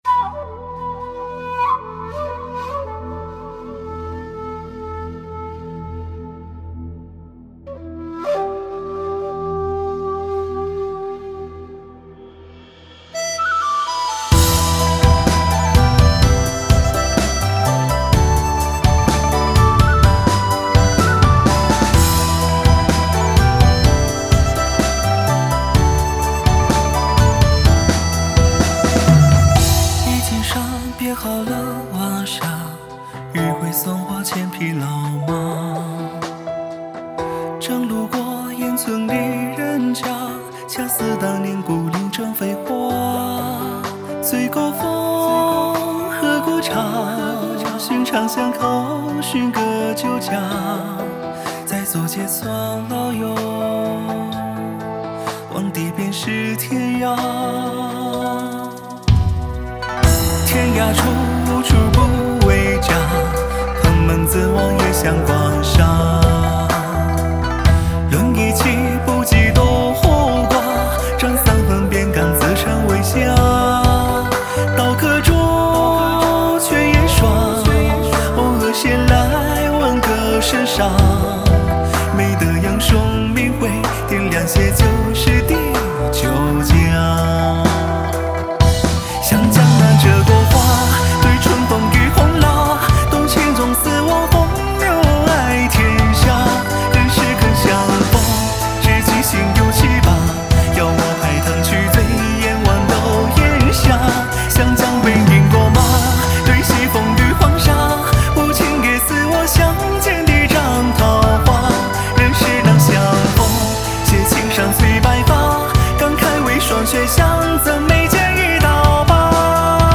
谱内音轨：架子鼓
曲谱类型：鼓谱